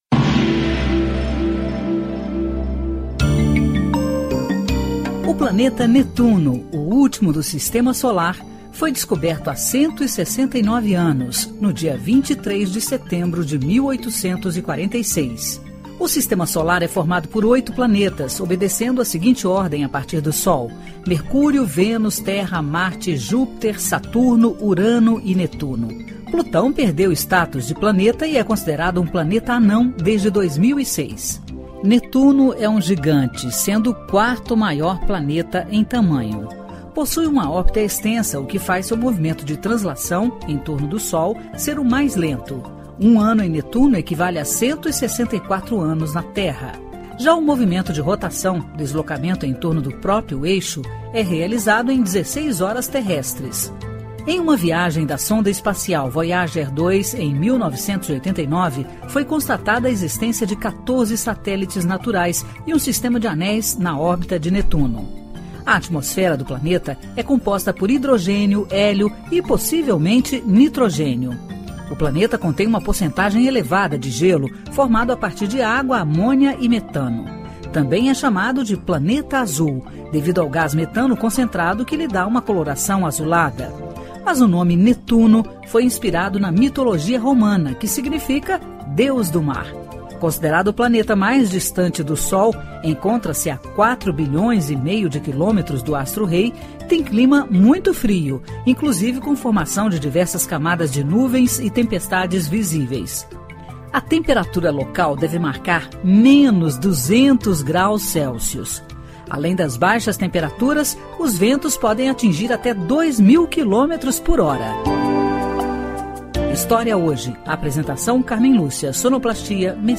História Hoje: Programete sobre fatos históricos relacionados às datas do calendário.